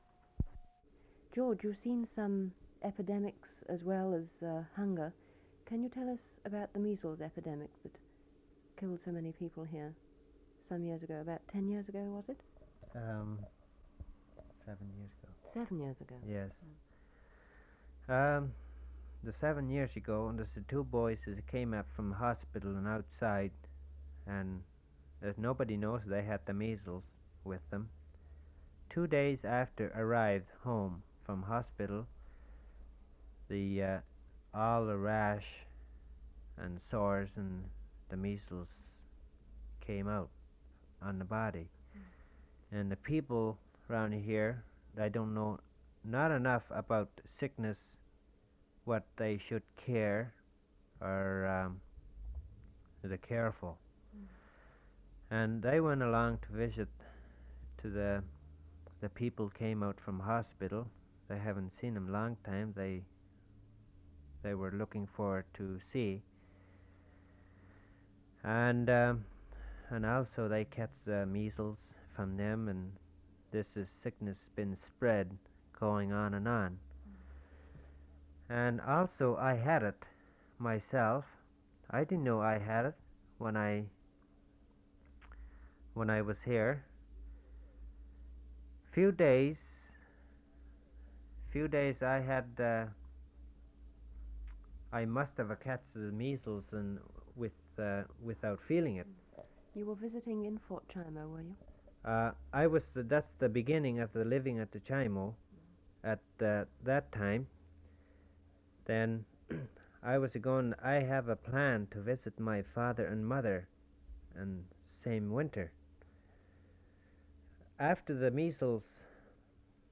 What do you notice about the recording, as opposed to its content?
He stood up slowly and announced our tape recording session was ended.